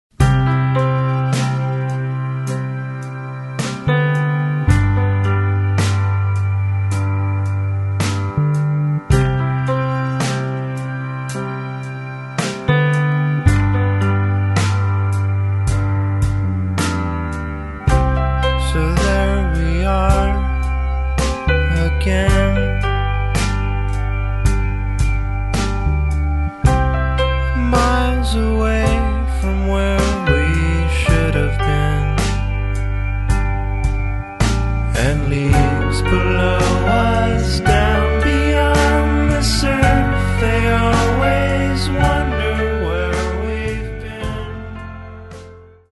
giving the songs a much more layered, labyrinthine feel